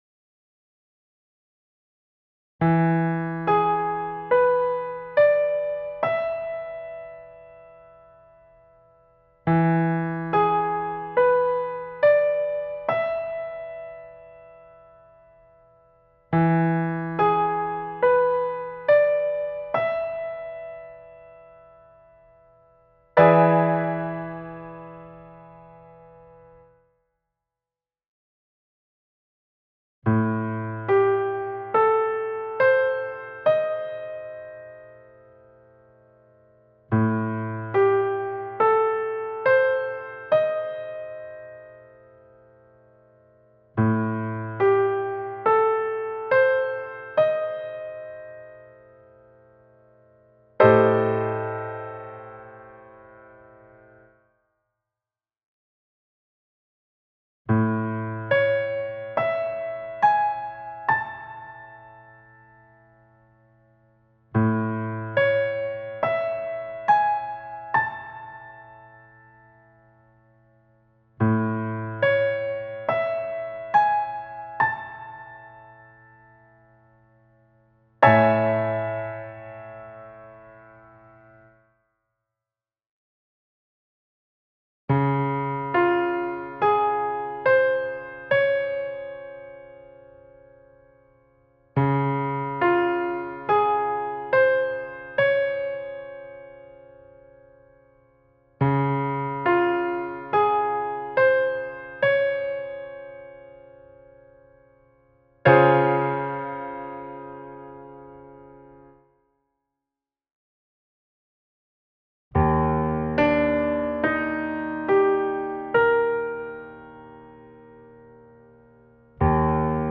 Riconoscimento e identificazione degli arpeggi(quadriadi in 1°-2°-3° rivolto)